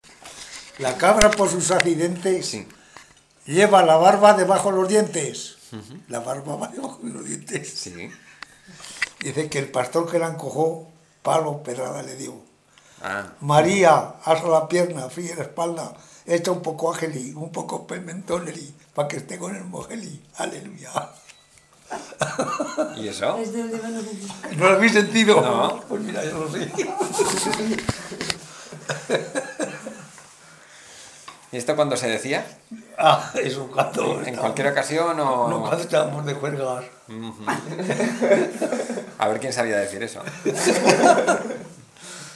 Cancioncillas infantiles y juveniles